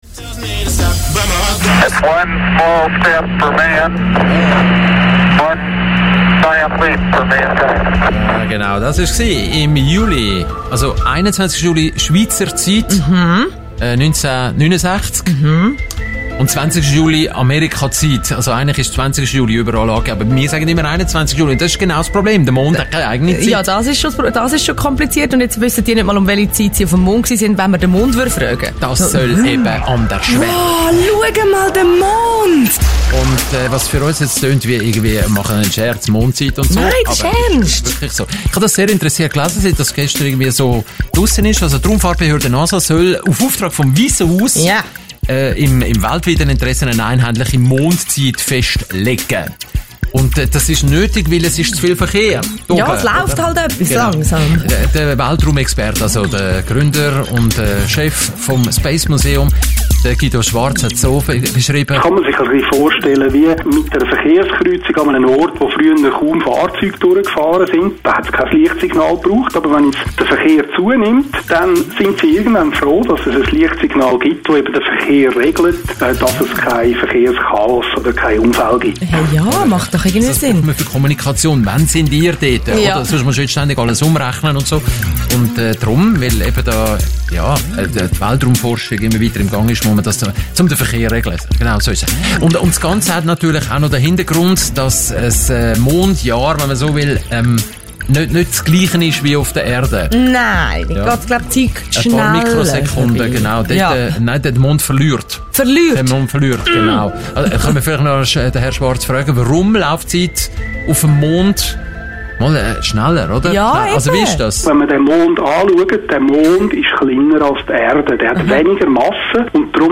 Radiobeitrag Teil 2